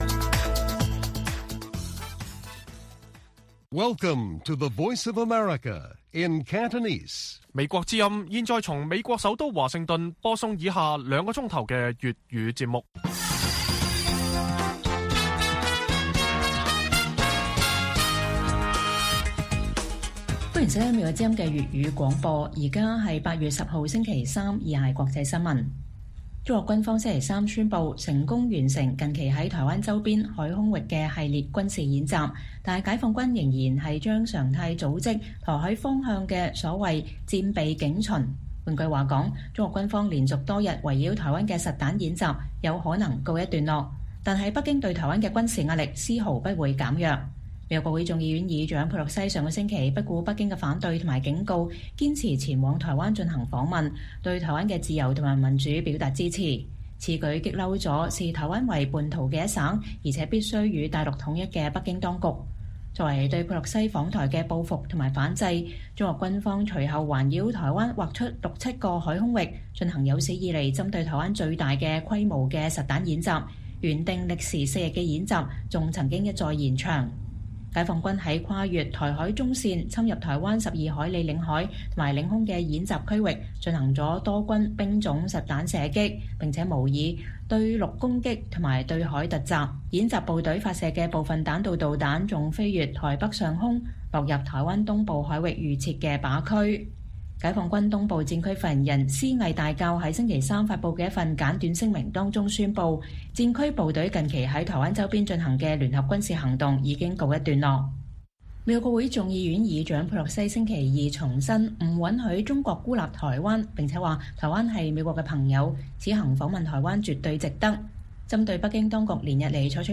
粵語新聞 晚上10-11點: 中國軍方宣布台灣周邊軍演“完成”，台海警巡將常態化